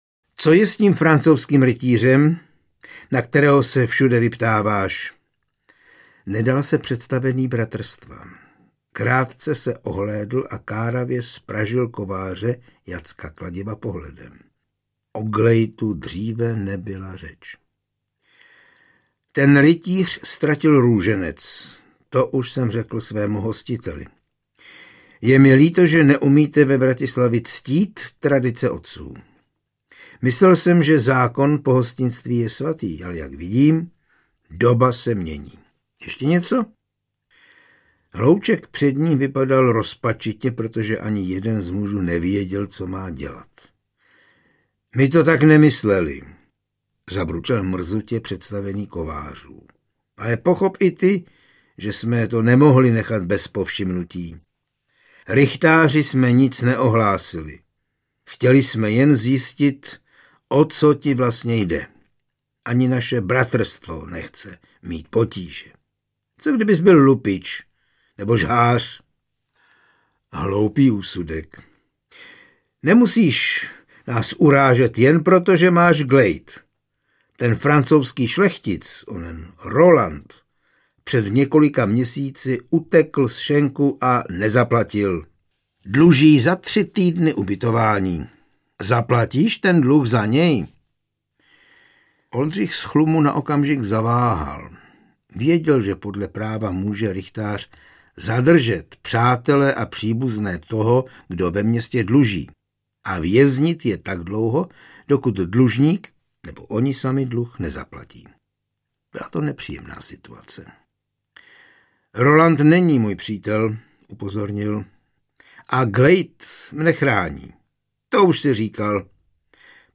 Speciální edice na přání posluchačů, bez hudebních předělů a podkresů.
Ukázka z knihy
oldrich-z-chlumu-zlociny-za-hranicemi-kralovstvi-audiokniha